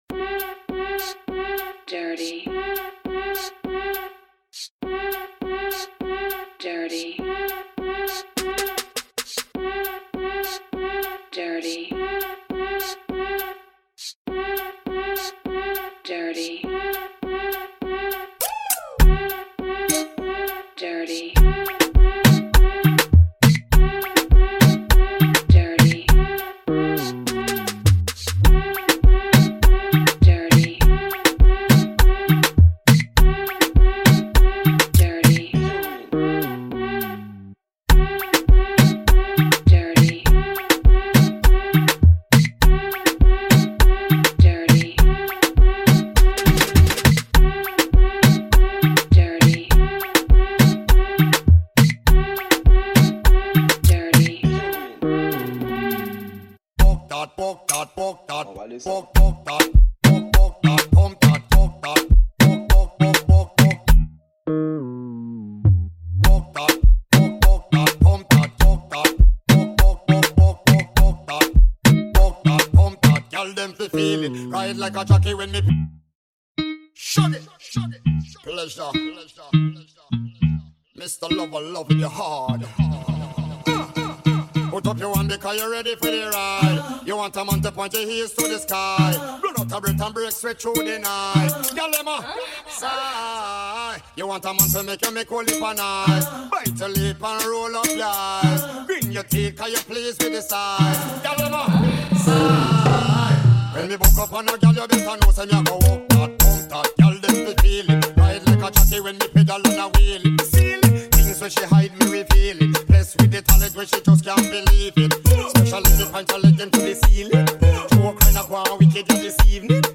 Liveset
Genre: House